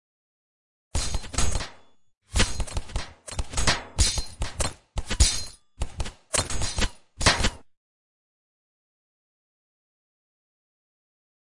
Download Shooting sound effect for free.
Shooting